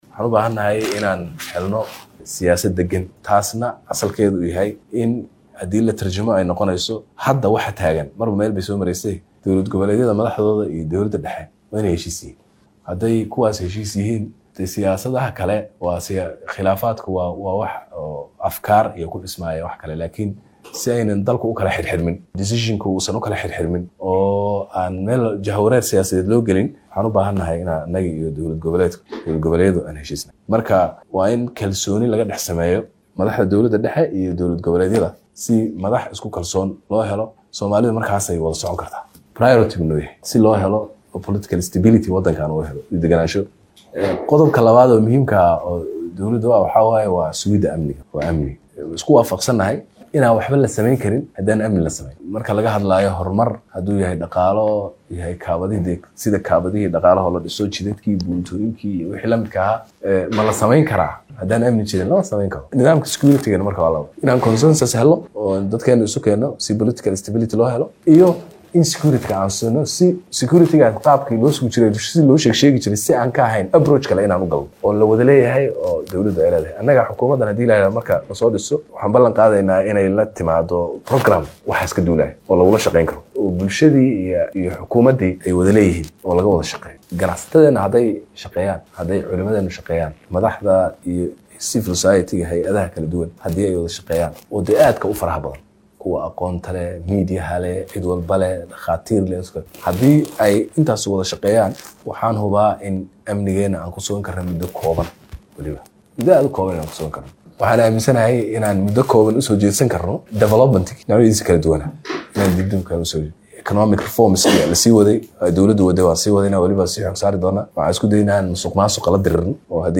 Ra’iisul Wasaaraha oo kulamo wadatashi ah ka wada magaalada Muqdisho ayaa sheegay in Bulshada Soomaaliyeed iyo Xukuumadda ay isku si u wada Shaqeyn doonaan, islamarkaana Xukuumaddiisa ay ku Shaqeyn doonto waxkasta oo saameeynaya Shacabka si ay u helaan Shaqo wanaagsan ayna kaga duwanaan doonaan Xukuumadihii ka horreeyay.